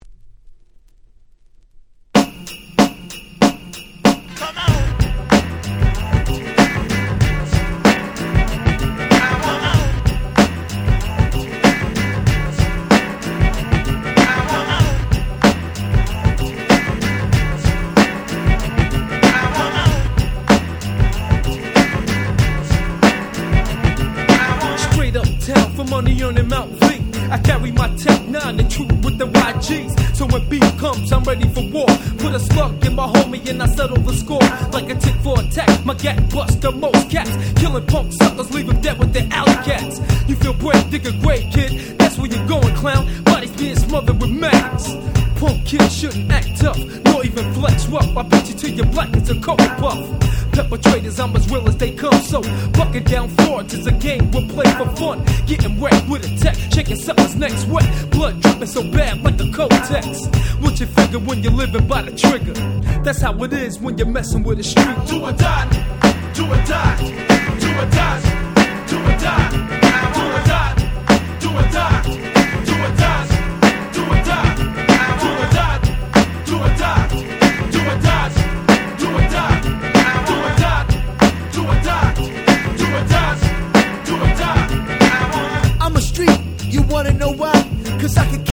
93' Nice Hip Hop !!